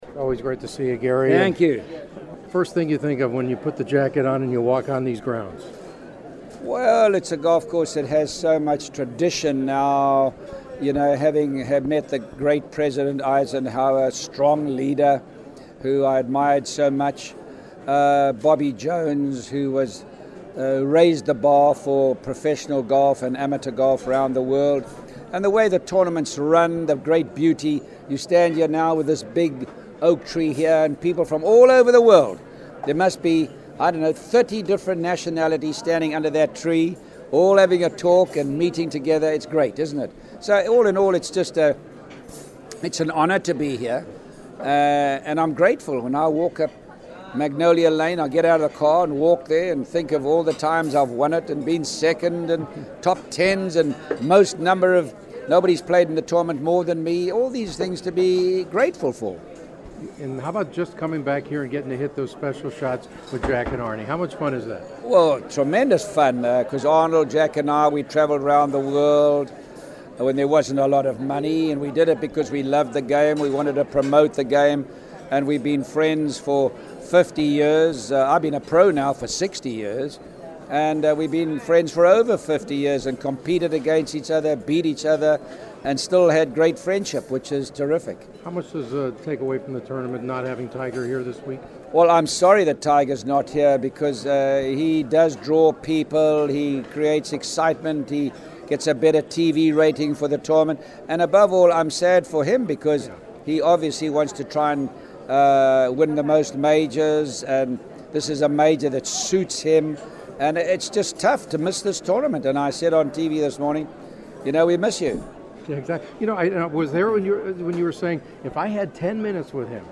The following are some of my Masters preview interviews leading into Thursday’s first round.
on Wednesday at the Masters